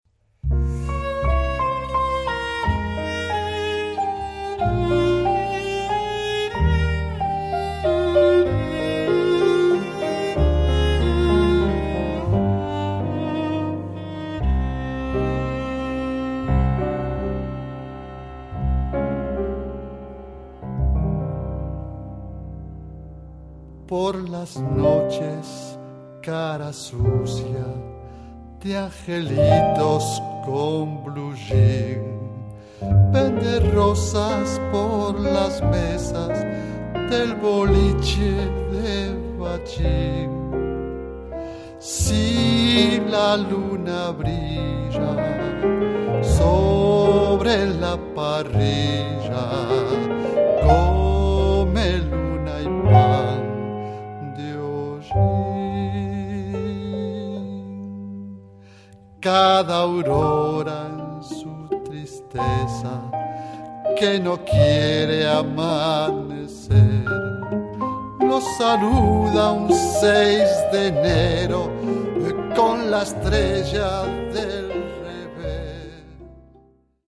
fonctionne actuellement comme un quintette de jazz
Guitare/chant/arrangement
Piano
Bandonéon
Violon alto
Contrebasse